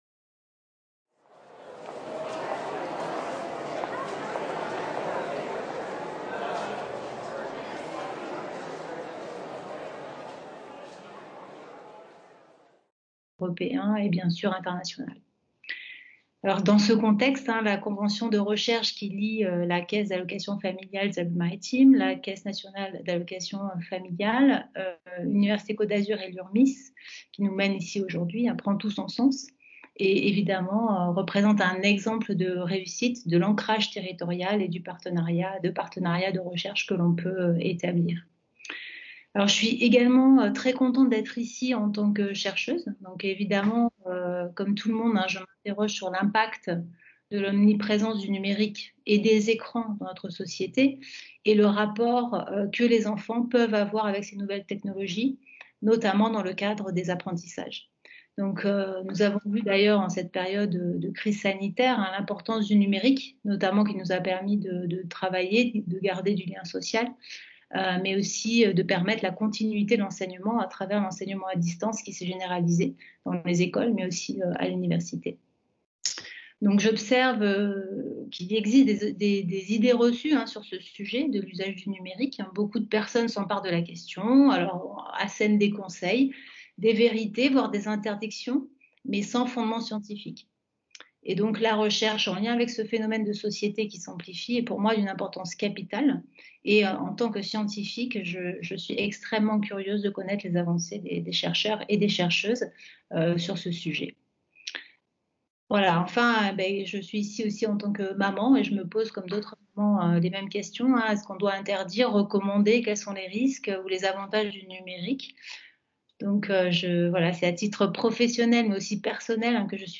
Enregistrement visioconférence